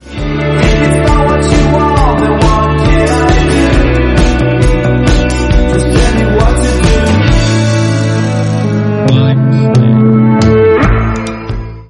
un titre estival et un clip frappé.